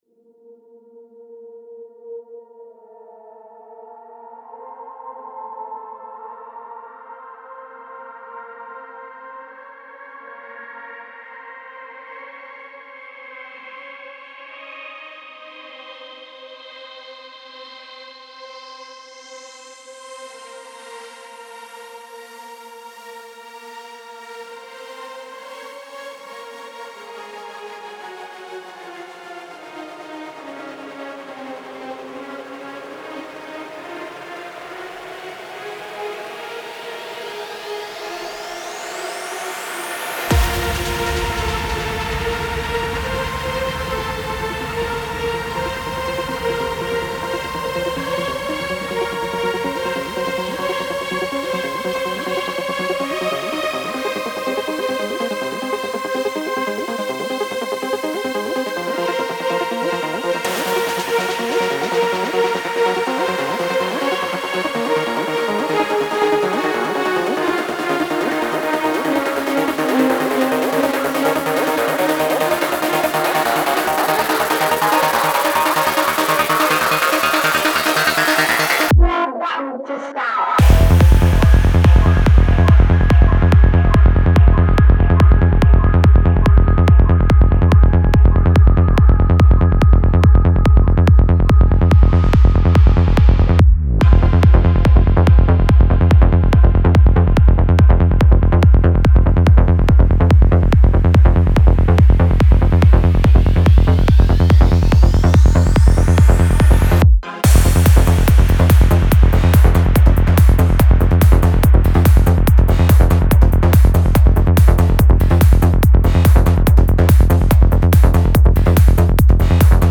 Файл в обменнике2 Myзыкa->Psy-trance, Full-on
Style: FullOn